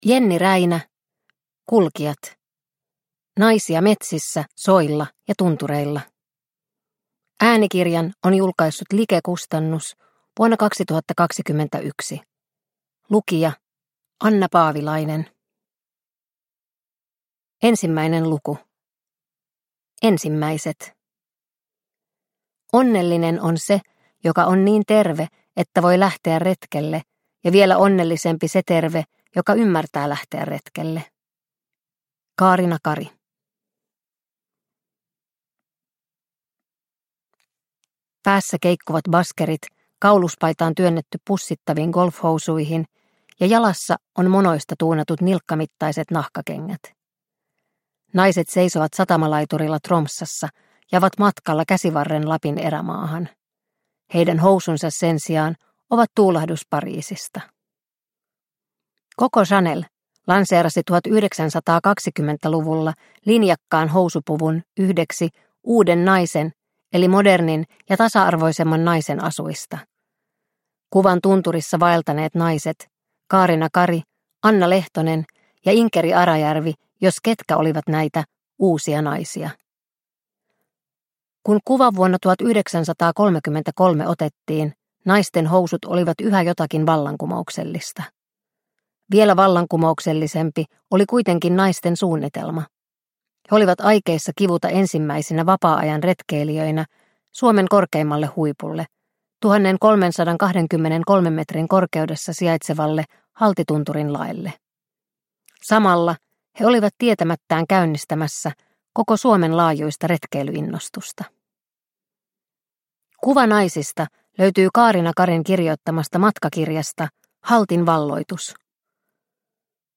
Kulkijat – Ljudbok – Laddas ner